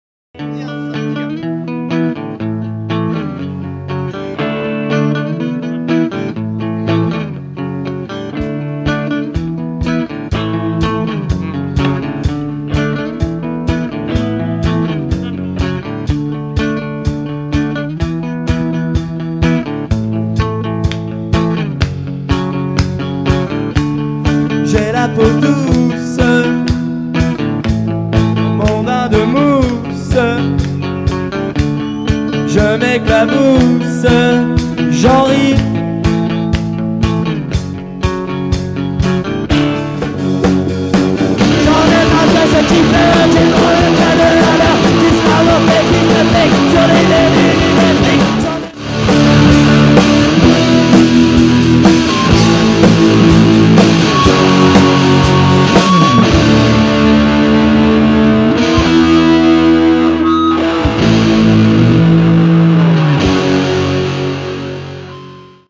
Reprise version hard